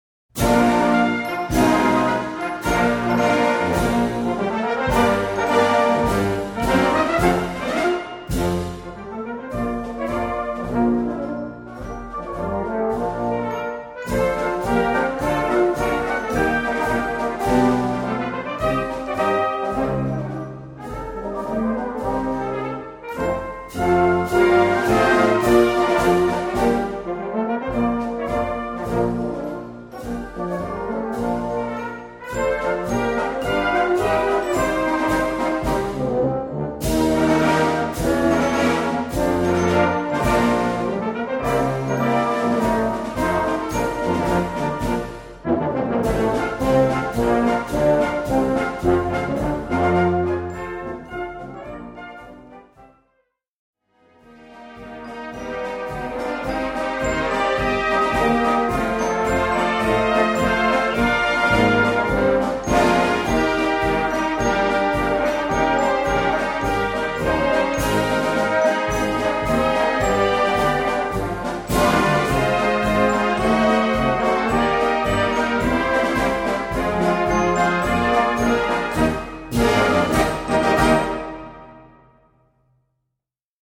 Gattung: Marsch
3:00 Minuten Besetzung: Blasorchester PDF